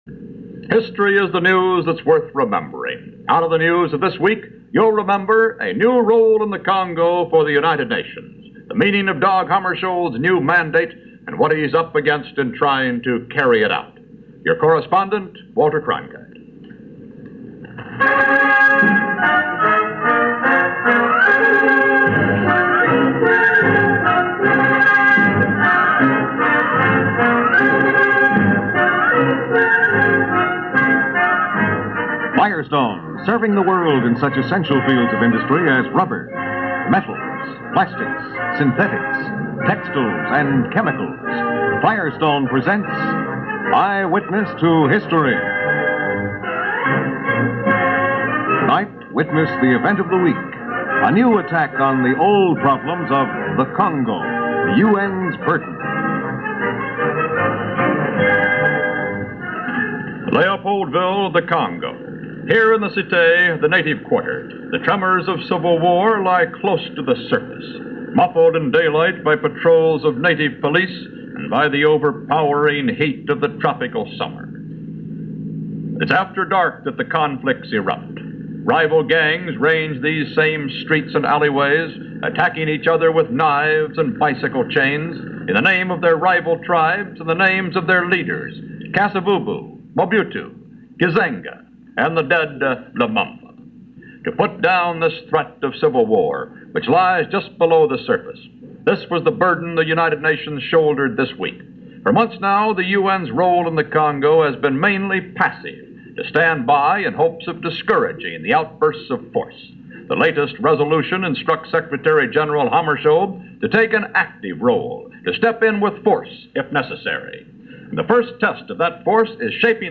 Narrated by Walter Cronkite – this episode of the documentary/news series Eyewitness To History, focuses on the recent clashes and civil war that broke out in the Belgian Congo just at the period of their independence. It was originally aired on February 24th 1960 and came right as UN Peacekeepers were flying into The Congo to establish a tense and tenuous peace.